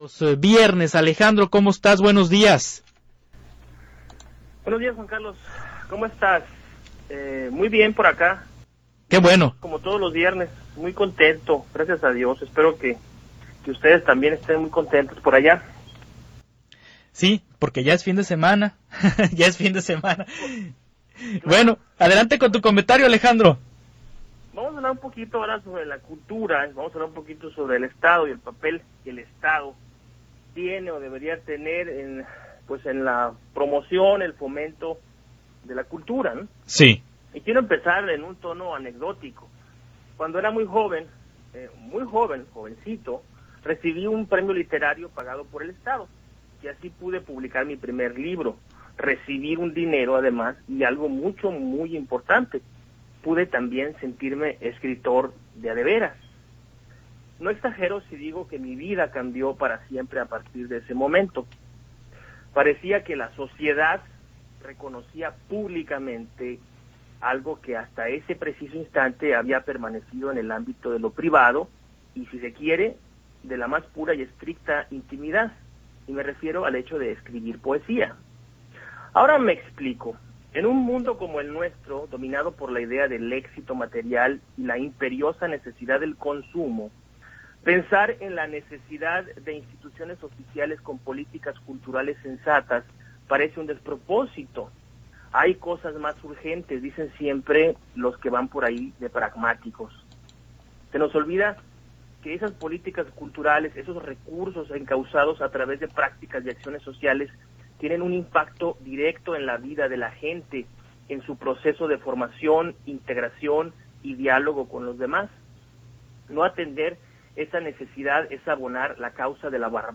Transmisión en radio